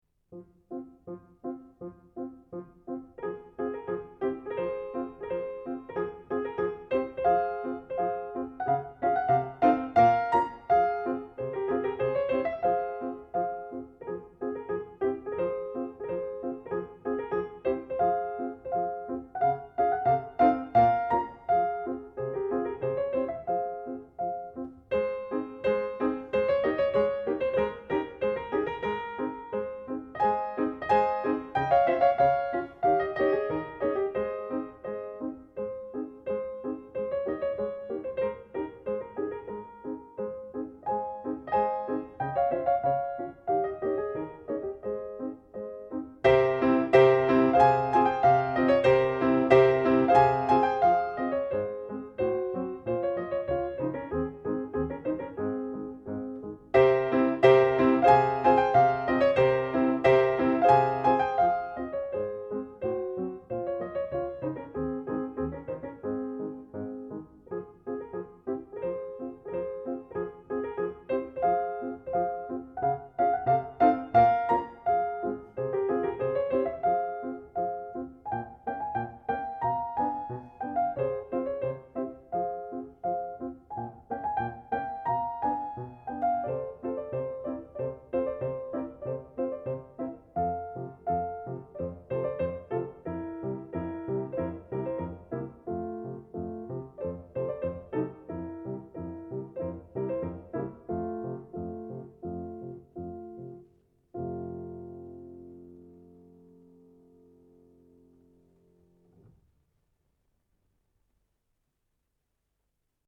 Піяніст
Шубэрт.#3 in Fa min. (Allegretto moderato)